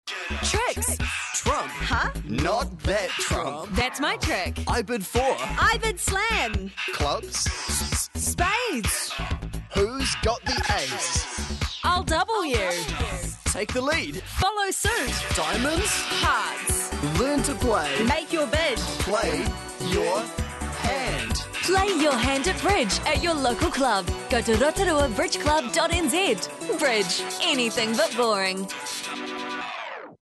Radio Advertising
radio ad.mp3